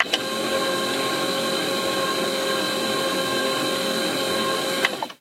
Звуки механизма робота
Все аудиофайлы записаны с реальных устройств, что придает им естественность.
Звук колес робота в движении